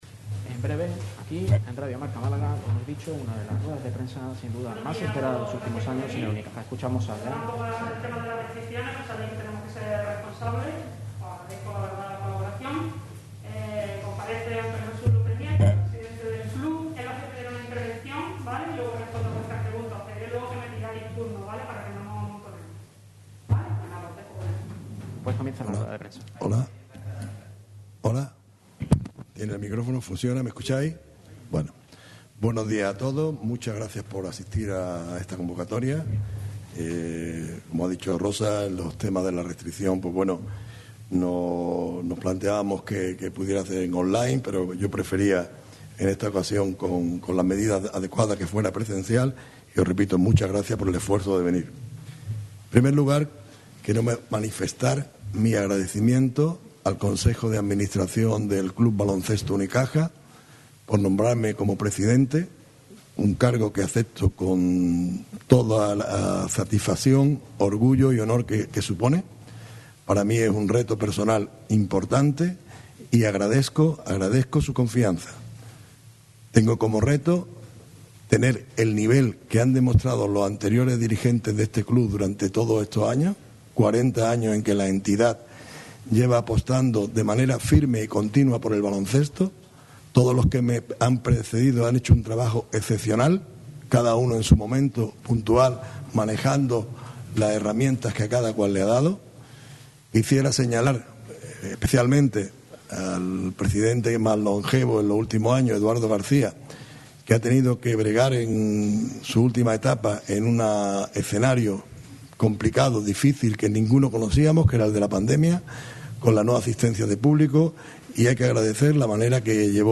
El nuevo presidente del Unicaja Málaga, Antonio Jesús López Nieto, compareció en una rueda de prensa como nuevo dirigente de la entidad.